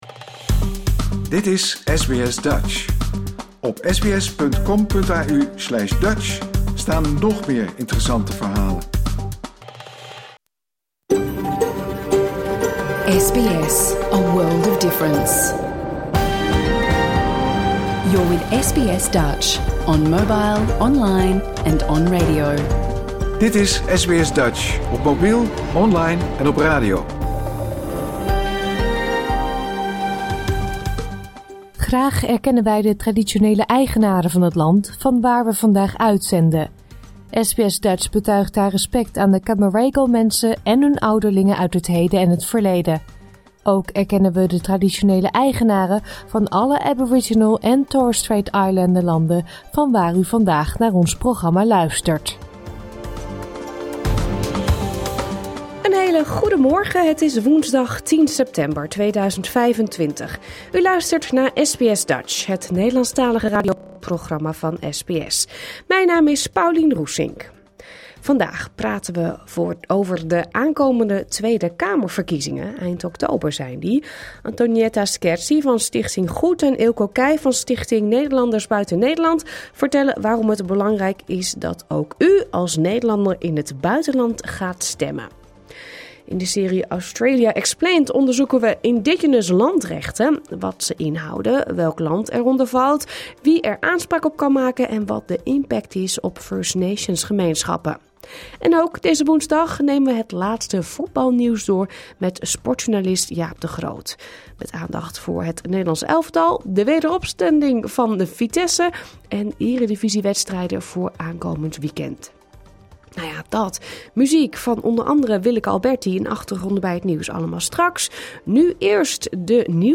Luister hier de uitzending van 10 september 2025 (bijna) integraal terug.